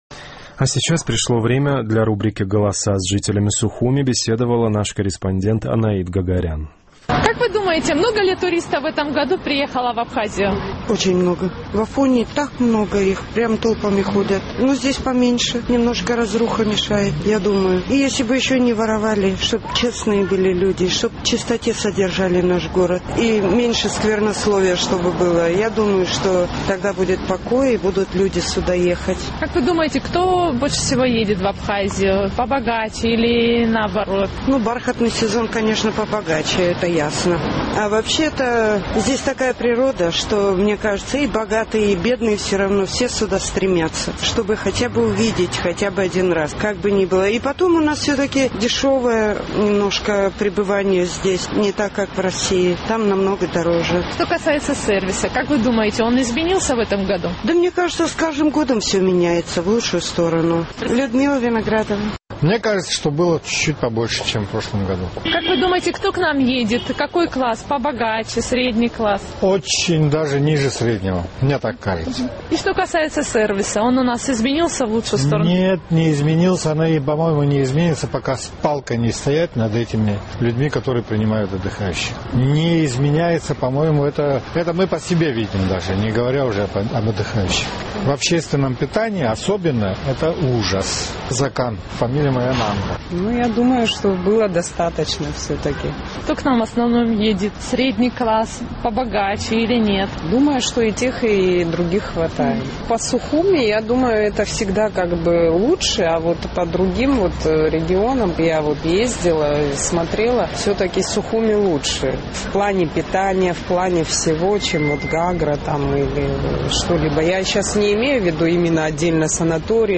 Наш сухумский корреспондент поинтересовалась у жителей абхазской столицы, много ли туристов посетило в этом году республику, какой социальный слой преобладает среди отдыхающих и изменился ли туристический сервис в лучшую сторону.